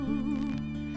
Here's an example containing a number of them close together, before and after they've been interpolated (click images to play samples):
A vinyl waveform including several small scratches loaded into digital sound editing software